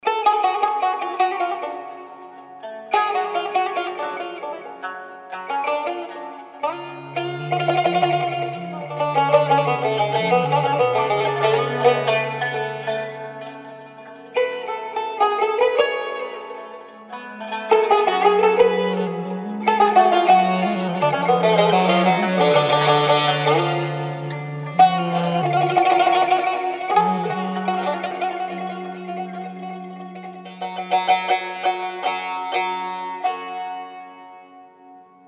رینگتون عاشقانه(رمانتیک) اذری بی کلام